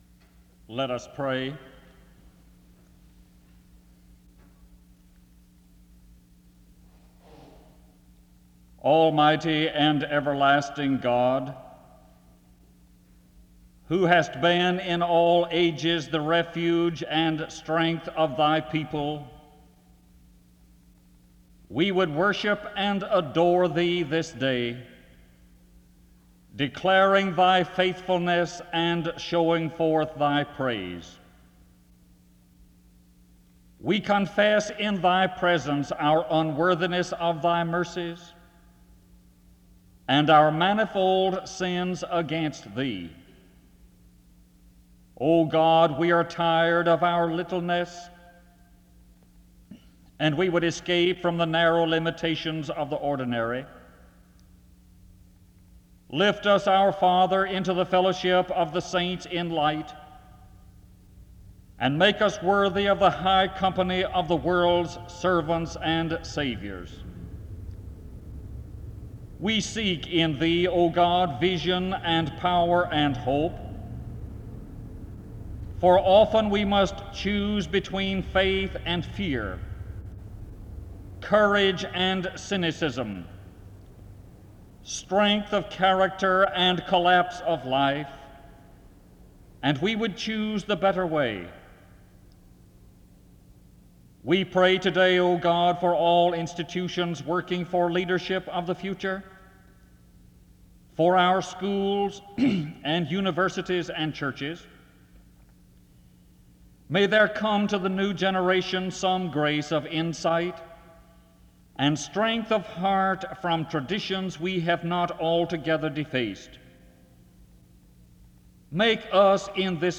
SEBTS Chapel and Special Event Recordings - 1970s